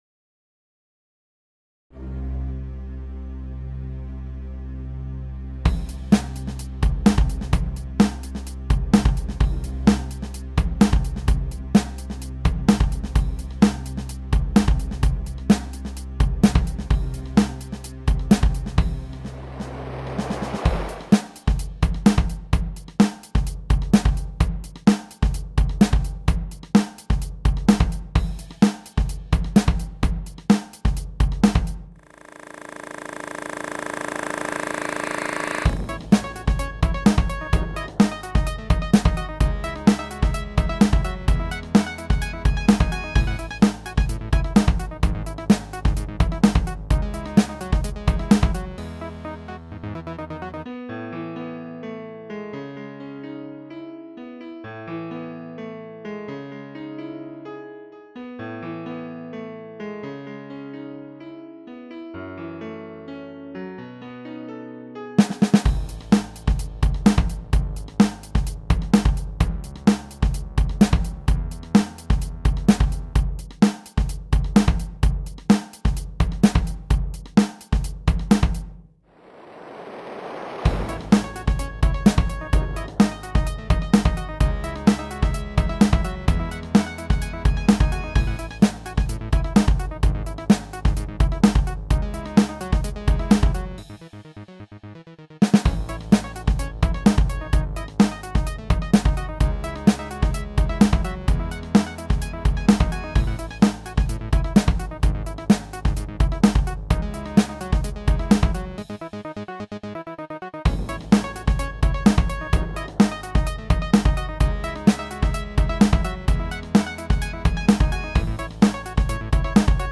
минусовка версия 242375